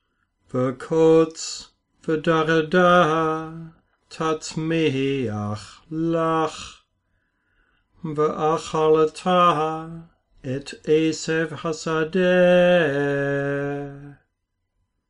sing along with me a few times.